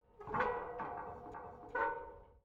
metal_creak1.wav